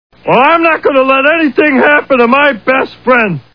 The Simpsons [Barney] Cartoon TV Show Sound Bites